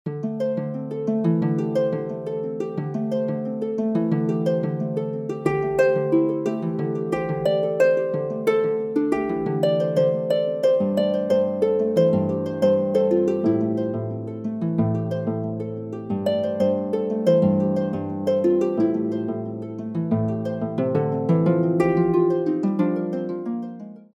Music for an Imaginary Harp